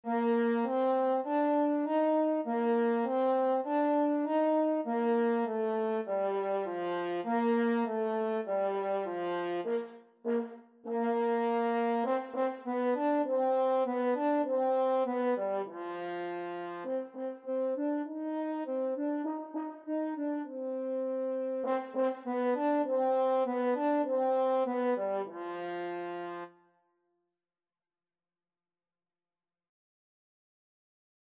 F major (Sounding Pitch) C major (French Horn in F) (View more F major Music for French Horn )
4/4 (View more 4/4 Music)
F4-Eb5
French Horn  (View more Beginners French Horn Music)
Classical (View more Classical French Horn Music)